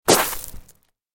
دانلود آهنگ تصادف 12 از افکت صوتی حمل و نقل
دانلود صدای تصادف 12 از ساعد نیوز با لینک مستقیم و کیفیت بالا